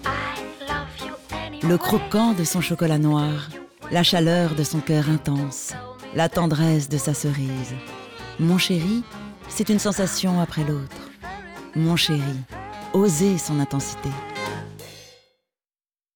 Voix sensuelle - Publicité Mon chéri